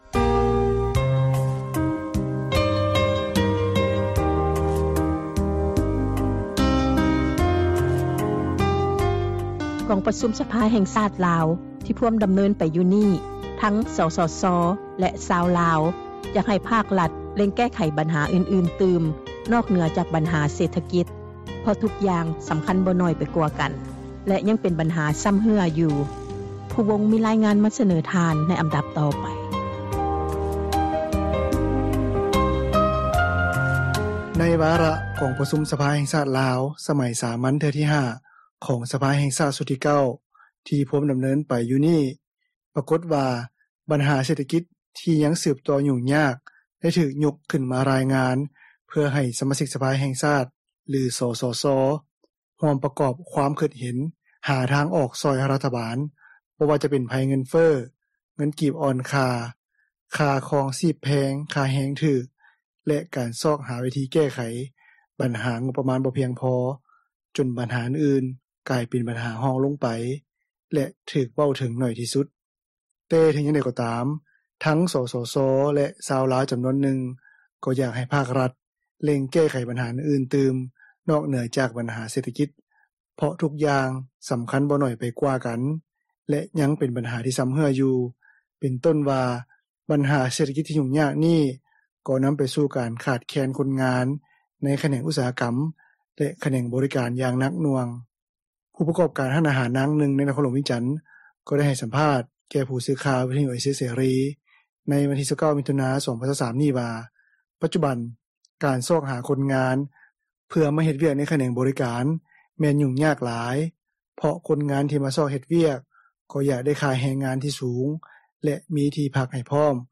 ຜູ້ປະກອບການຮ້ານອາຫານ ນາງນຶ່ງ ໃນນະຄອນຫຼວງວຽງຈັນ ກໍໄດ້ໃຫ້ສັມພາດແກ່ຜູ້ສື່ຂ່າວ ວິທຍຸ ເອເຊັຽ ເສຣີ ໃນວັນທີ 29 ມິຖຸນາ 2023 ນີ້ວ່າ ປັດຈຸບັນ ການຊອກຫາຄົນງານ ເພື່ອມາເຮັດວຽກໃນຂແນງບໍຣິການ ແມ່ນຫຍຸ້ງຍາກຫຼາຍ ເພາະຄົນງານທີ່ມາຊອກເຮັດວຽກ ກໍຢາກໄດ້ຄ່າແຮງງານທີ່ສູງ ແລະ ມີທີ່ພັກໃຫ້ພ້ອມ ຈົນຜູ້ປະກອບການຈຳນວນຫຼາຍ ບໍ່ສາມາດຈ້າງໄດ້ ແລະ ໃນເມື່ອຄົນງານລາວ ບໍ່ສາມາດເຮັດວຽກ ພ້ອມກັບມີທີ່ພັກອາສັຍກັບນາງຈ້າງໄດ້ ພວກເຂົາກໍຈະບໍ່ເຮັດ ແລະ ພາກັນໄປຊອກວຽກເຮັດງານທຳ ຢູ່ປະເທດໄທຍແທນ ຈົນນຳໄປສູ່ການຂາດແຄນຄົນງານ.